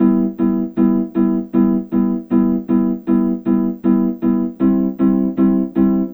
Track 11 - Piano Chords.wav